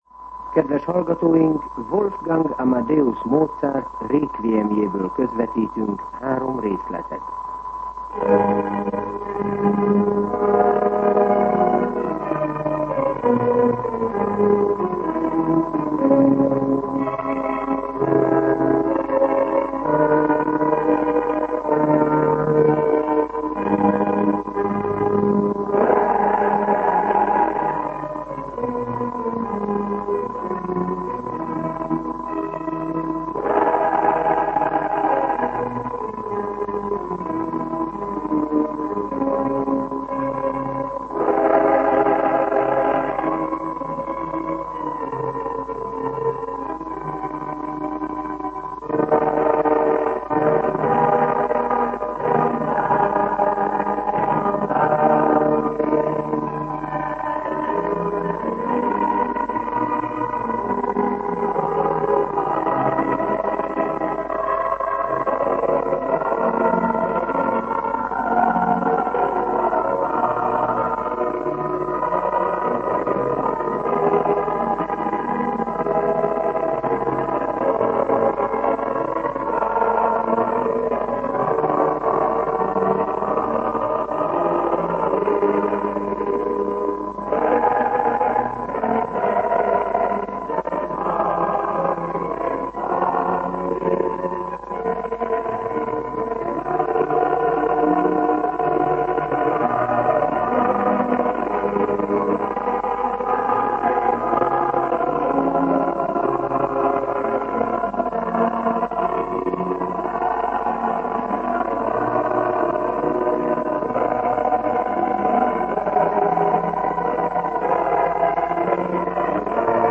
Zene